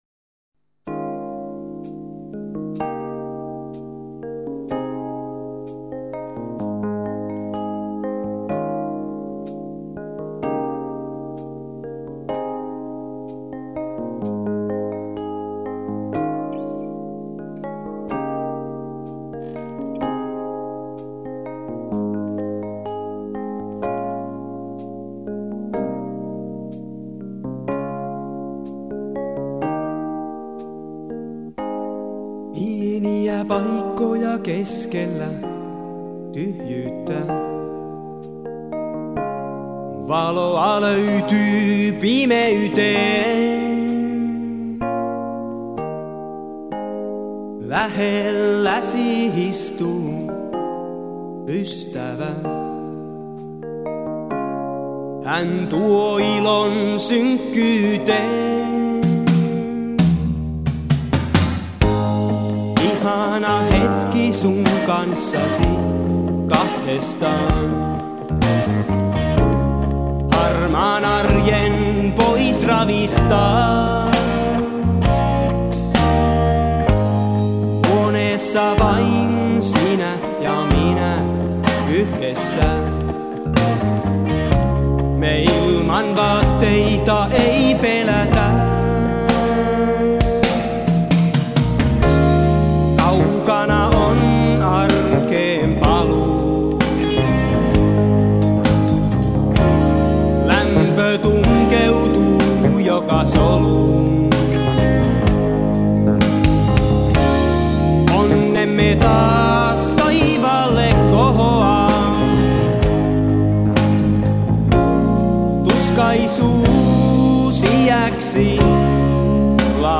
laulu, nauhaton basso
koskettimet
kitara
rummut